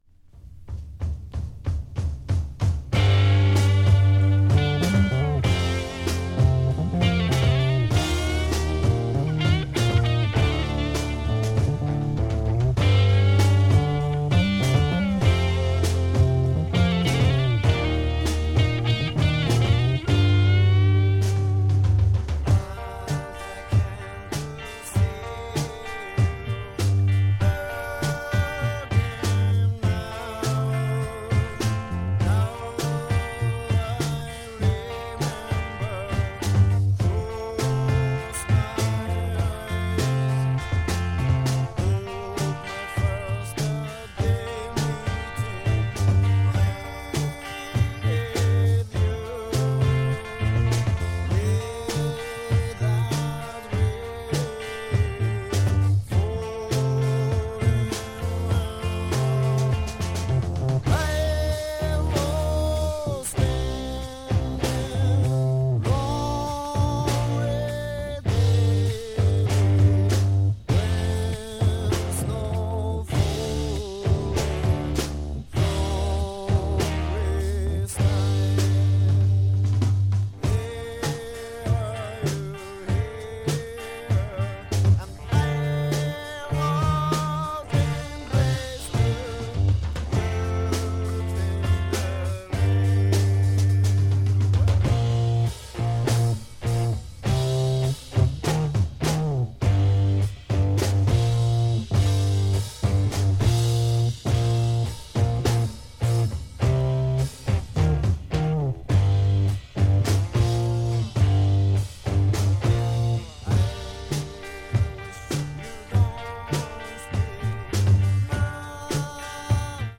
ヘヴィロックバンド
重たくブルージーでサイケデリックなサウンドが素晴らしいですね！